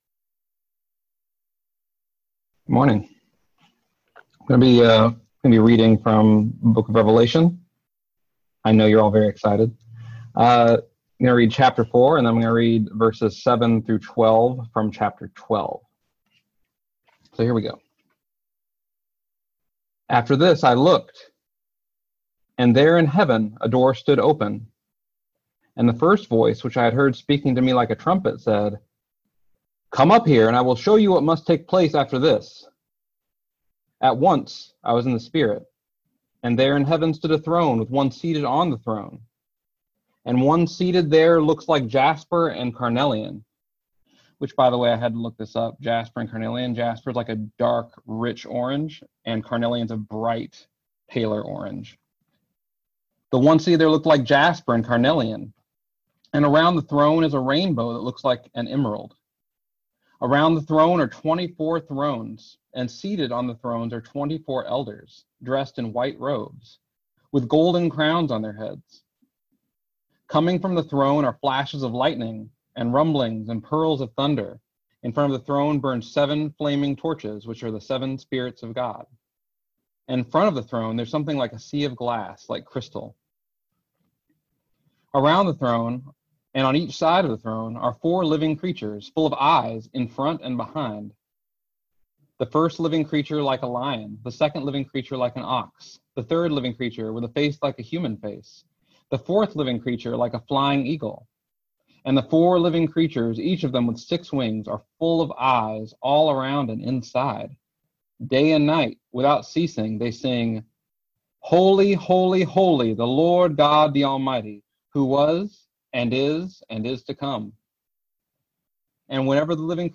Listen to the most recent message from Sunday worship at Berkeley Friends Church, “War in Heaven.”